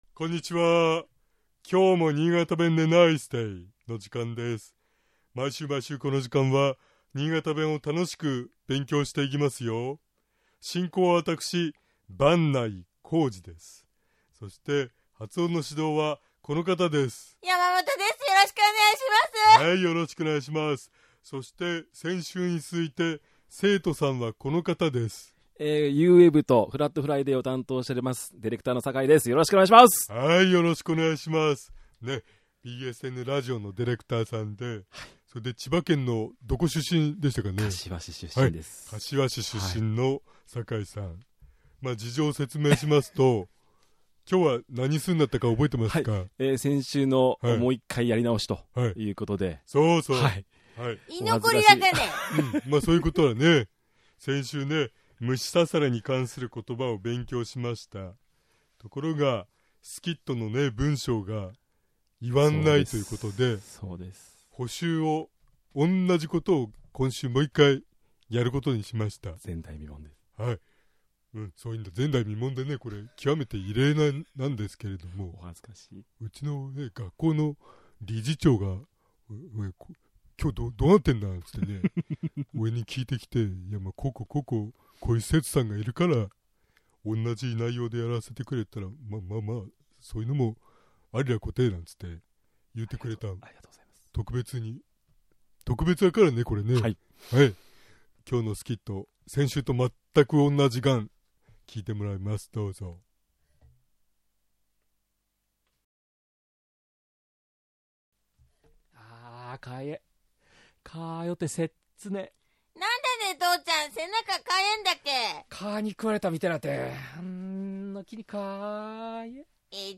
今日は、虫刺されに関する言葉について勉強しましょう。 虫に刺されてかゆいと言う場合、 新潟弁では「かゆい」ではなく「かぁいぇ」と発音します。
（発音練習） 「かぁいぇ」 「かぁよてせっつね」 「掻きぼっこす」 （スキット） （主人）あああああ、かぁいぇ。